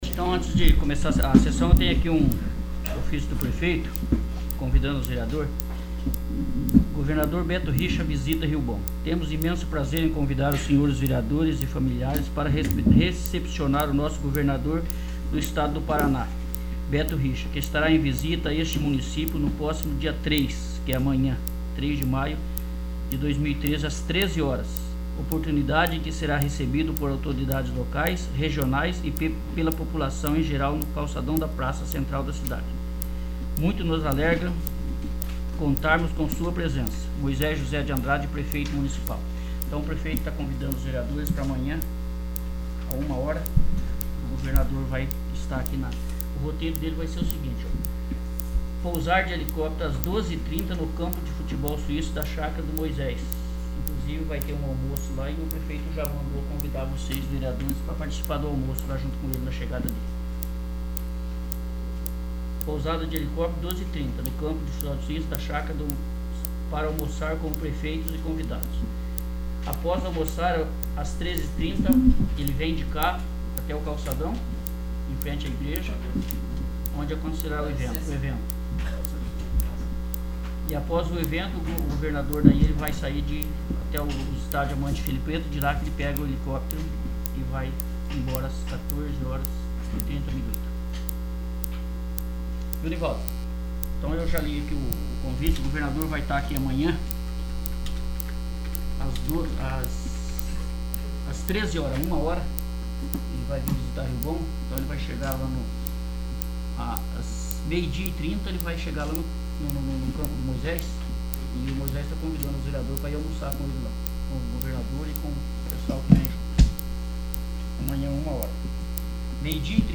12º. Sessão Extraordinária